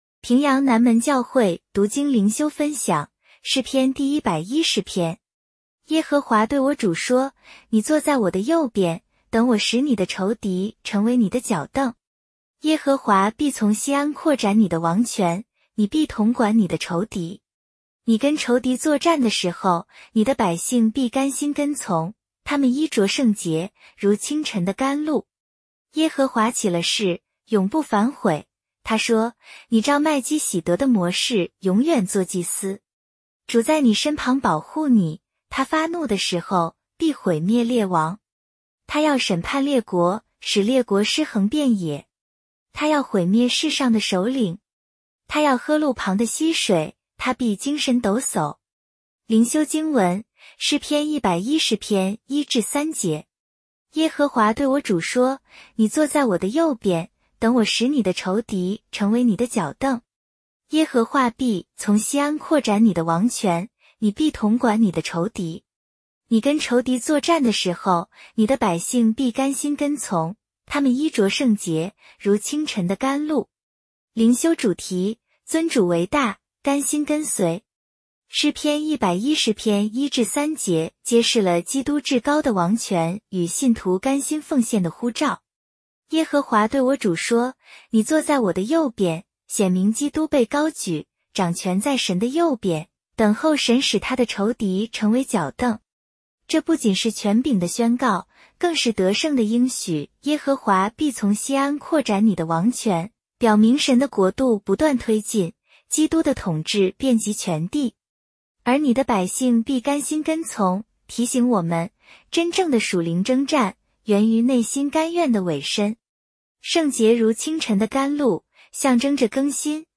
普通话朗读——诗110